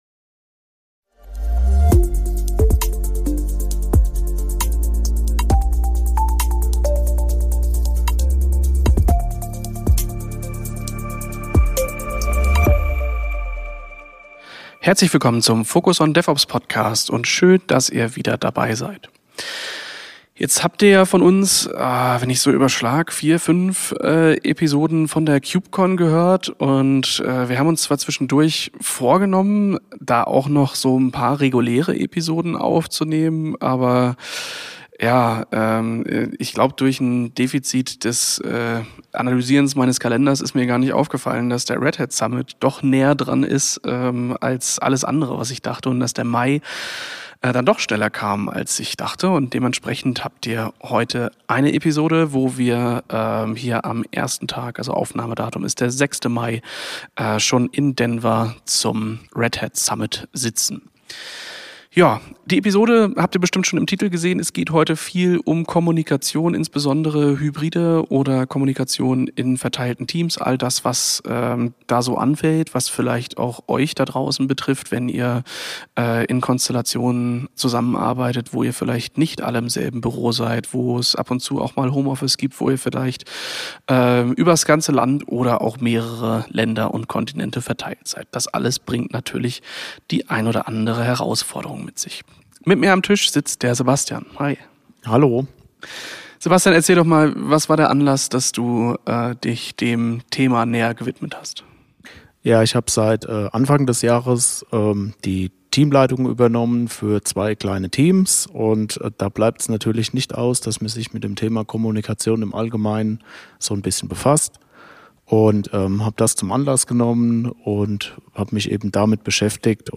Auf der Red Hat Summit in Denver diskutieren wir über das Management verschiedener Kommunikationskanäle und versuchen, Strategien zur Priorisierung von Informationen zu finden, um Wissen besser zu verteilen und Teams ein effektiveres Arbeiten zu ermöglichen.